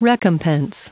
Prononciation du mot recompense en anglais (fichier audio)
Prononciation du mot : recompense